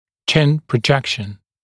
[ʧɪn prə’ʤekʃn][чин прэ’джэкшн]выступание подбородка вперед, проекция подбородка, переднезаднее положение подбородка